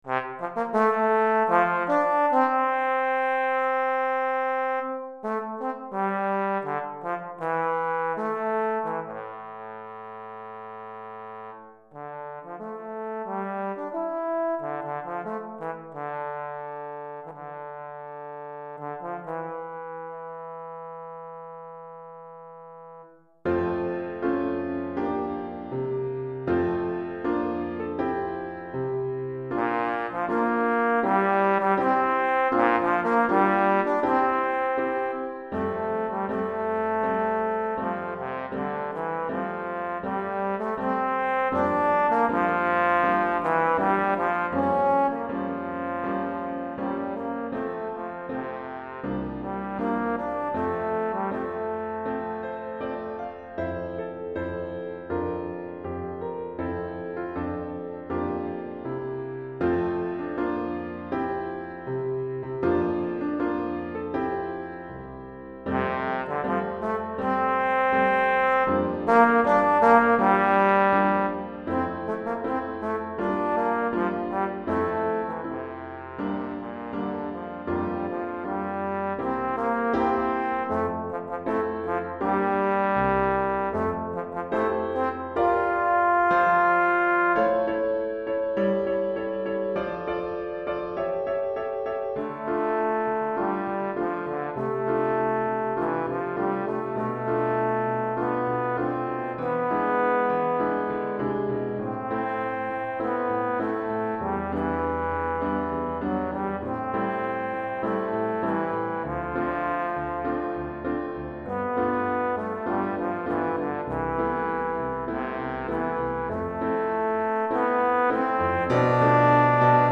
Pour trombone et piano DEGRE FIN DE CYCLE 1 Durée